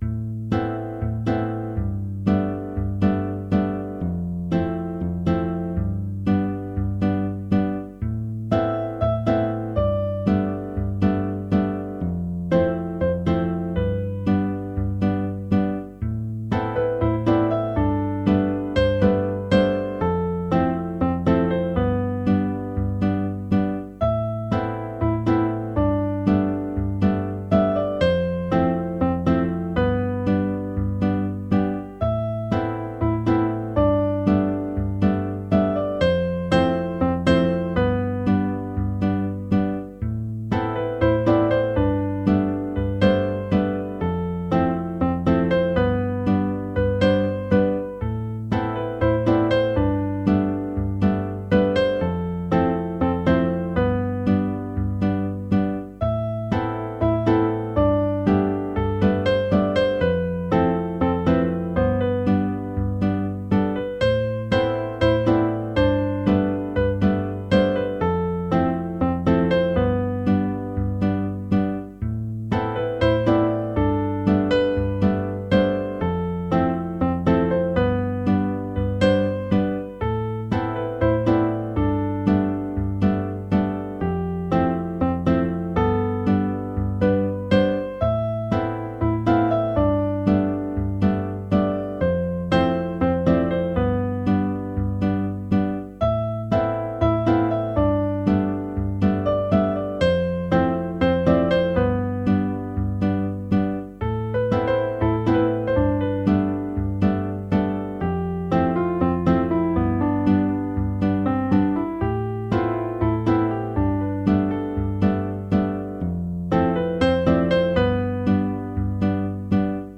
Chill out con aire de bossa